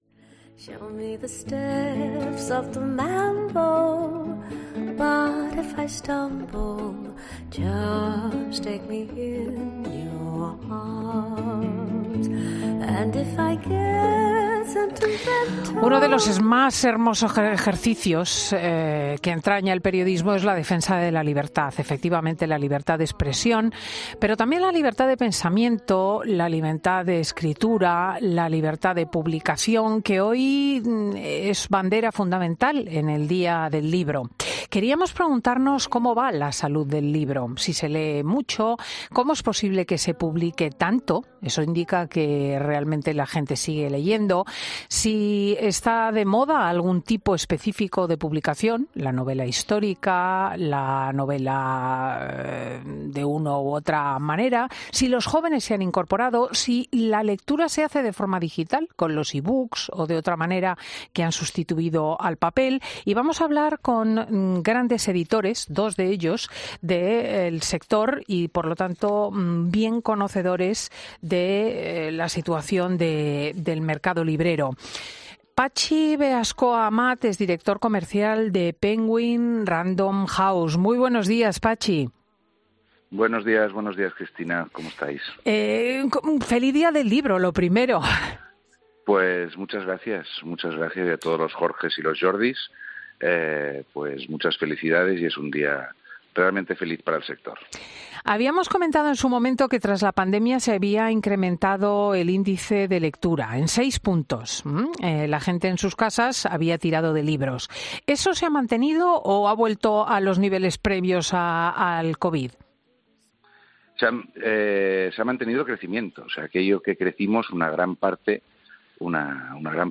En 'Fin de Semana' hablamos con dos expertos del sector editorial para conocer los hábitos de lectura de los españoles y los pronósticos para los...